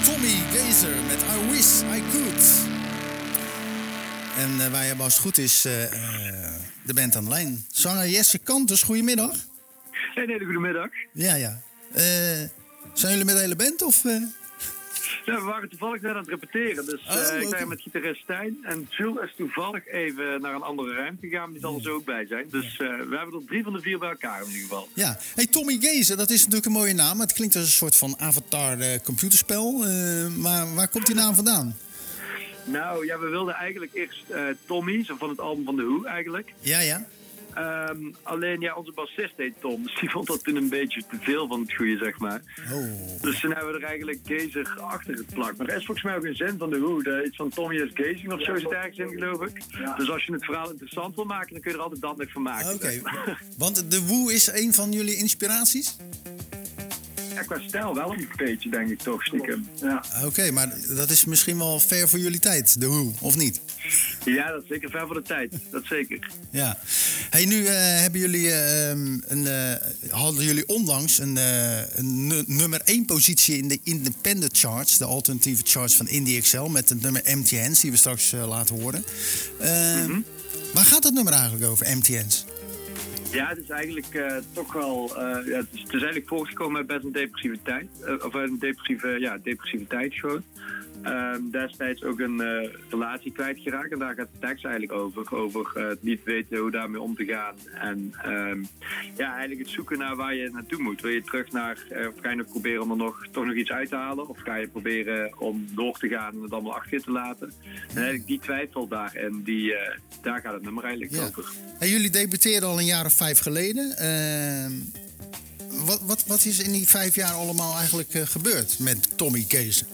Tijdens de wekelijkse editie van Zwaardvis belde we de Limburgse rockband Tommy Gazer die op dat moment in de studio zaten voor het uitwerken van nieuwe materiaal.